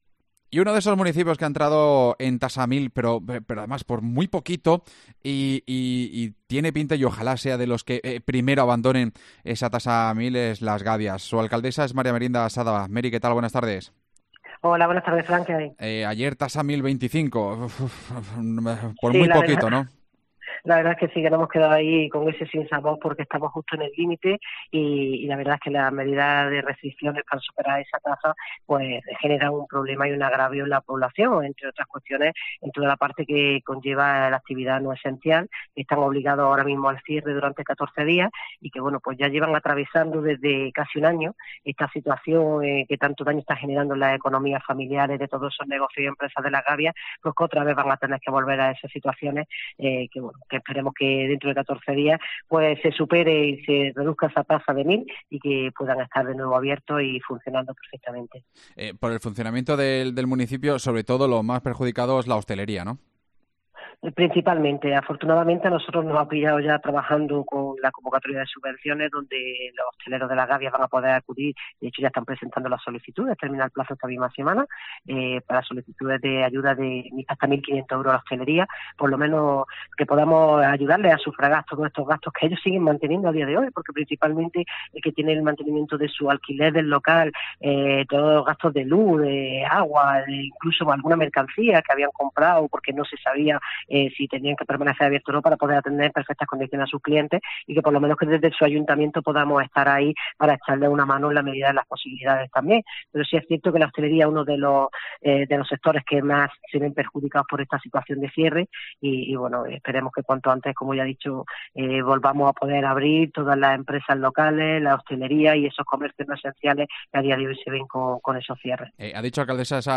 Hablamos con su alcaldesa María Merinda Sádaba